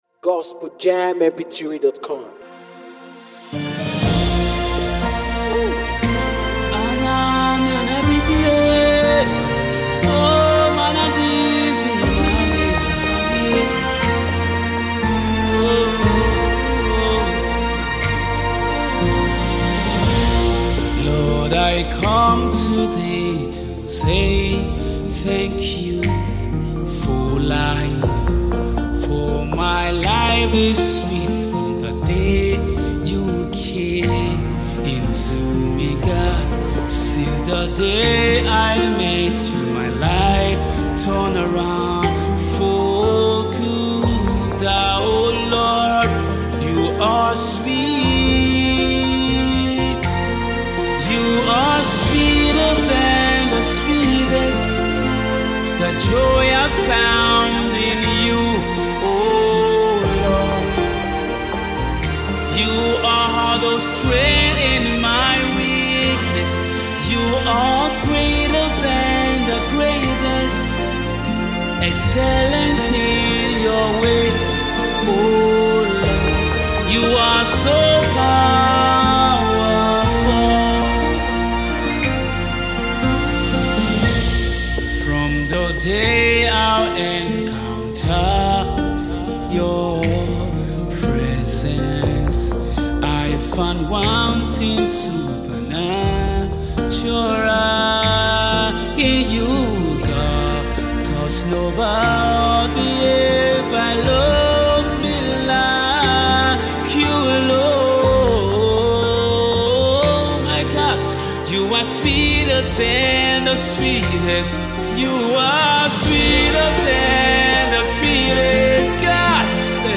soulful worship song